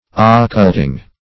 Occulting \Oc*cult"ing\, n. Same as Occultation .